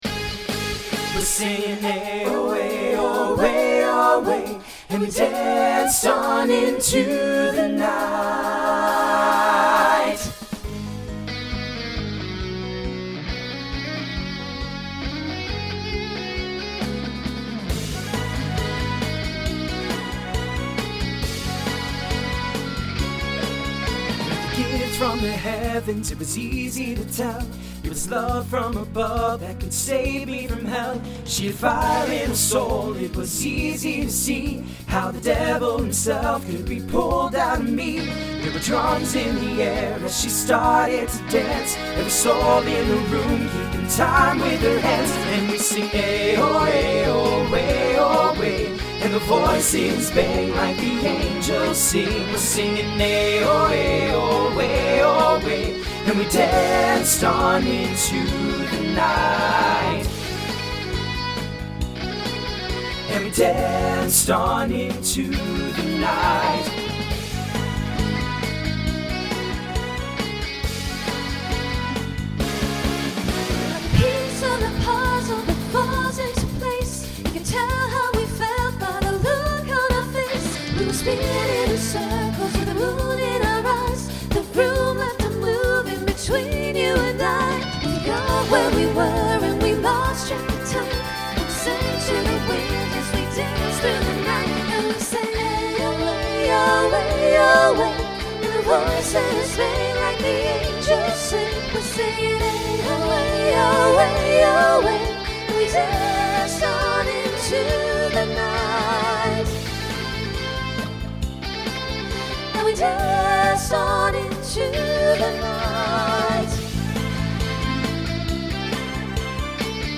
TTB/SSA
Voicing Mixed
Genre Rock